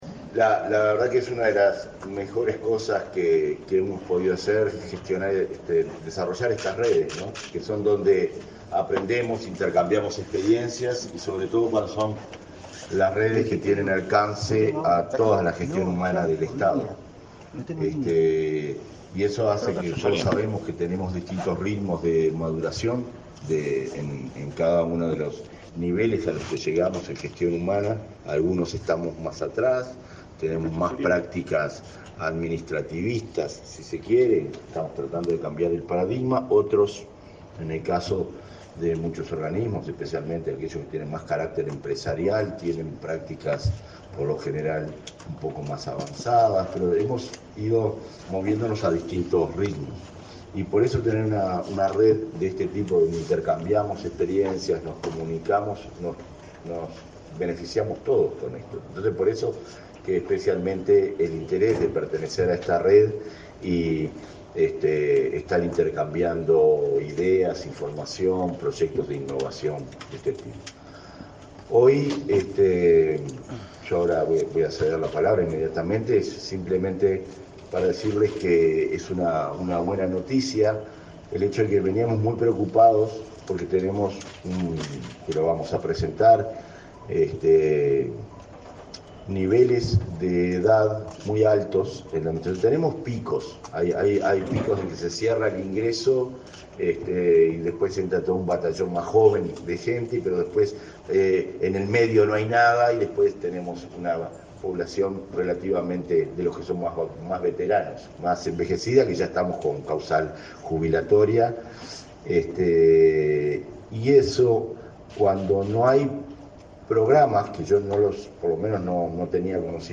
Palabras del director de la ONSC, Conrado Ramos